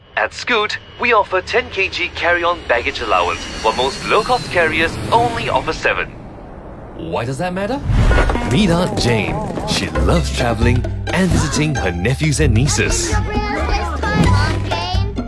Male
English (Neutral - Mid Trans Atlantic)
A warm, clear, and authoritative Singaporean voice.
My tone ranges from a friendly, conversational guy-next-door to a highly trusted, serious professional.
I deliver broadcast-quality audio with excellent diction and pacing.
Relatable Casual Ad